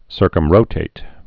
(sûrkəm-rōtāt)